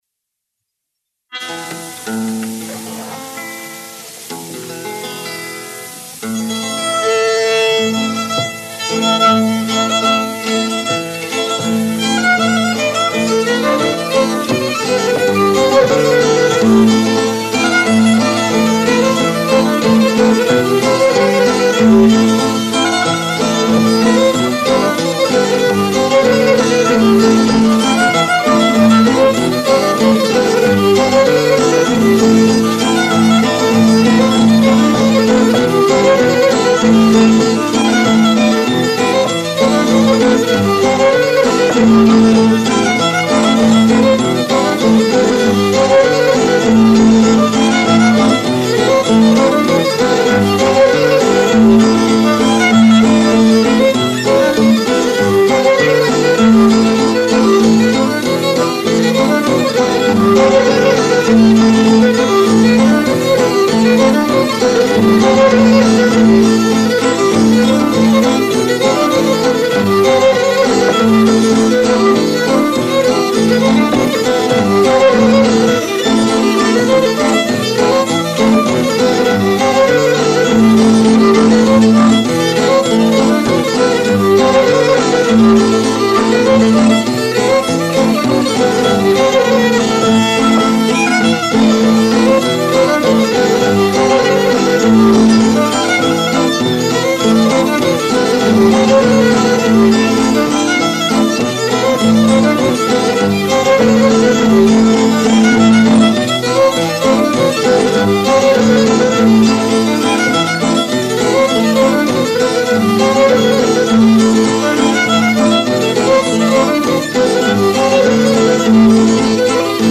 ΜΟΥΣΙΚΗ ΑΠΟ ΤΗΝ ΠΑΡΑΔΟΣΗ ΤΗΣ ΑΝΑΤΟΛΙΚΗΣ ΚΡΗΤΗΣ
10.Κοντυλιές στη La minore